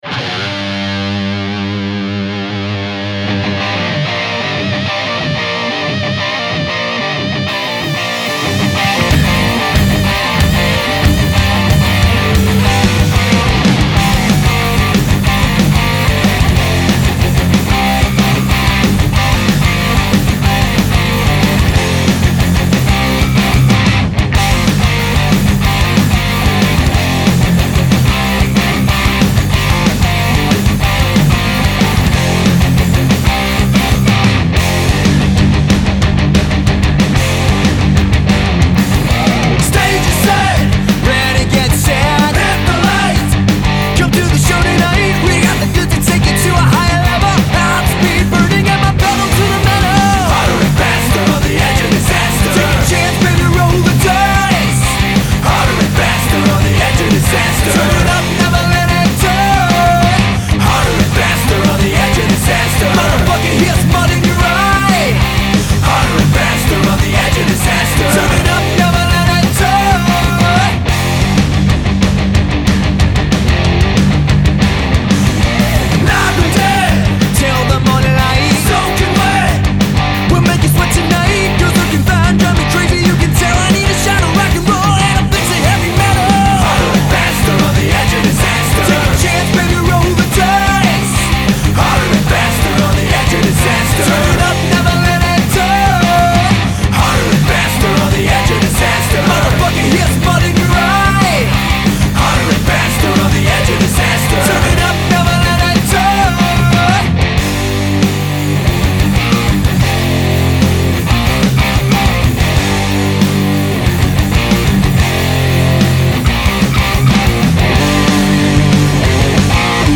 Genre: Arena Rock.